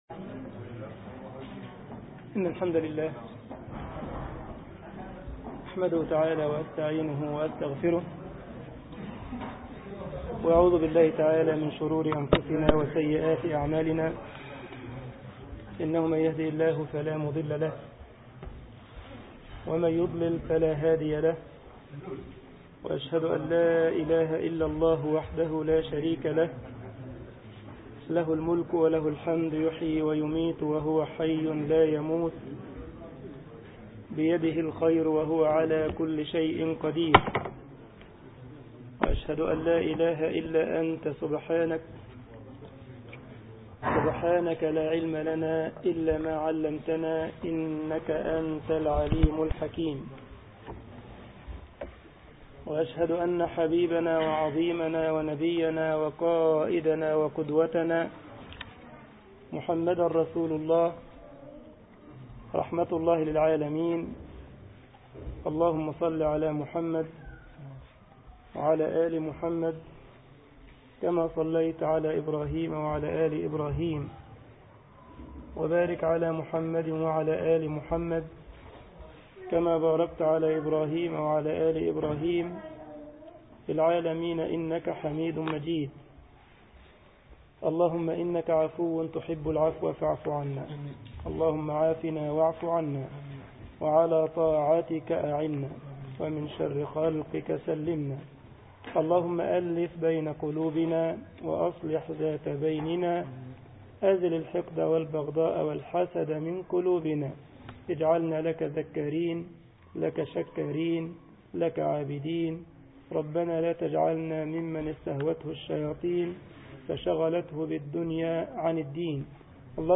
مسجد الجمعية الإسلامية بالسالند ـ ألمانيا درس